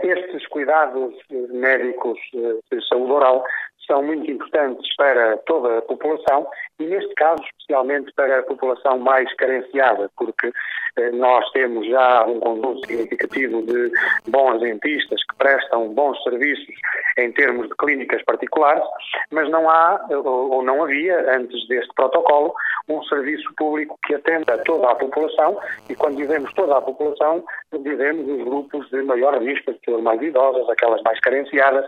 O acompanhamento da saúde oral na população mais vulnerável é fundamental, salienta o autarca José Eduardo Ferreira.